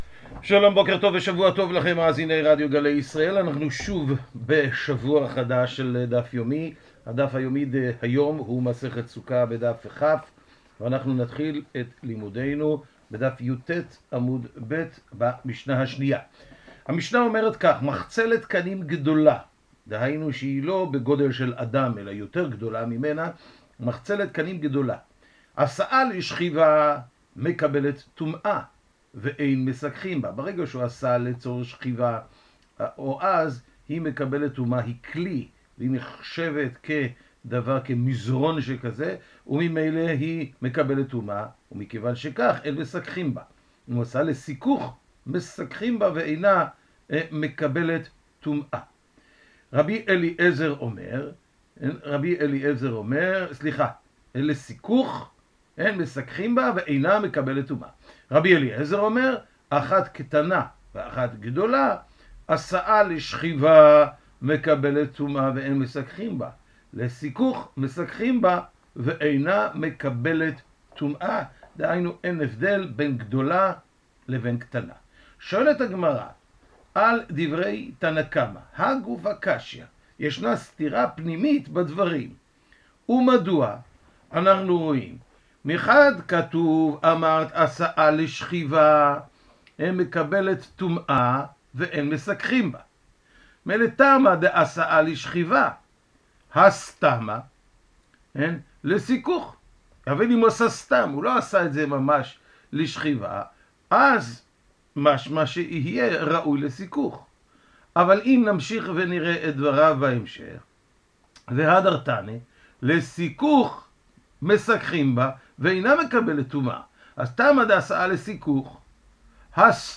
השיעור משודר בשעה 05:30 בבוקר ברדיו גלי ישראל וכל היום באתר סרוגים.